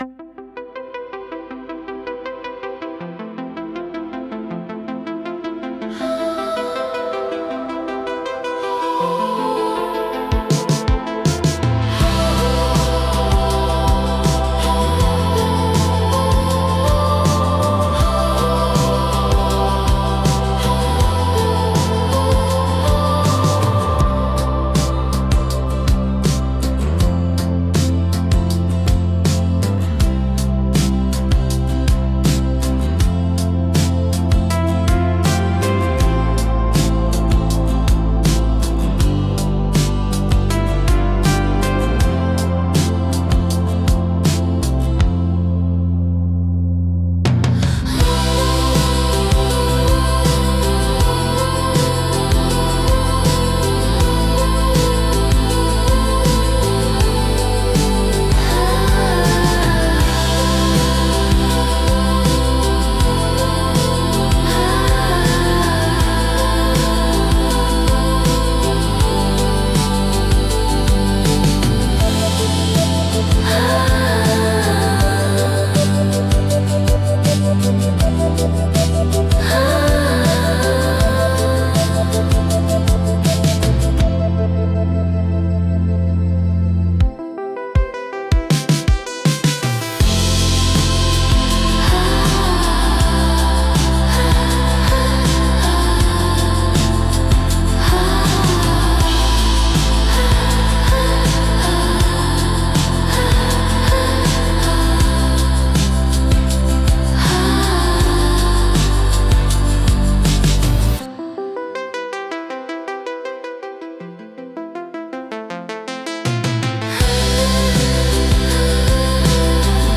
BGMセミオーダーシステムドリームポップは、繊細で幻想的なサウンドが特徴のジャンルです。
静かで美しい音の重なりが心地よく、感性を刺激しながらも邪魔にならない背景音楽として活用されます。